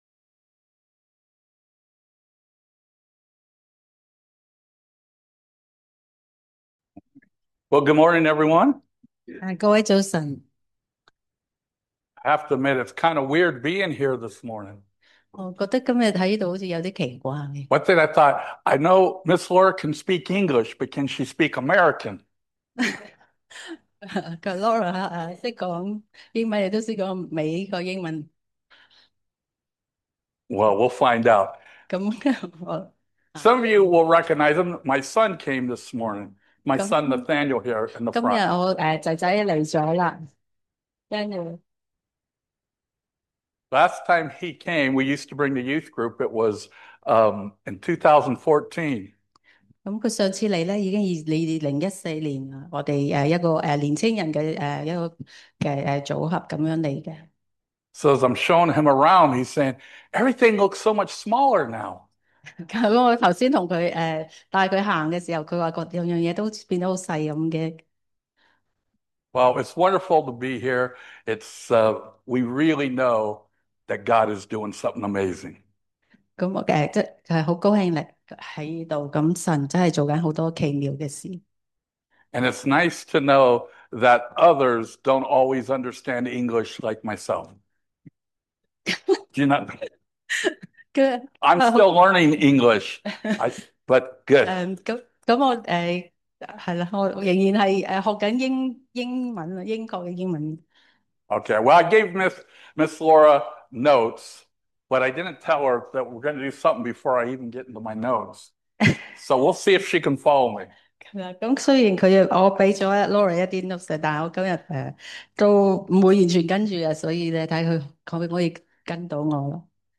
Service Type: Sunday Service
English with Cantonese Interpretation